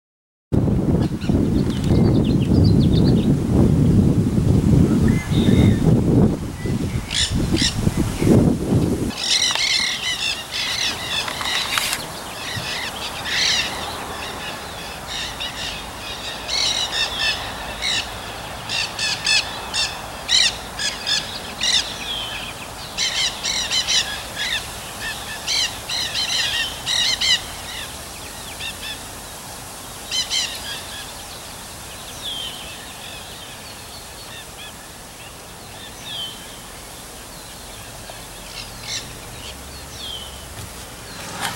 New Category and Audioclip: Nanday Parakeets
you will find the baby keetie says “I love you” to its momma. Later in the audio clip the momma keetie says “I love you too!” Then at the end of the audio clip the baby keetie says again: “I love you!’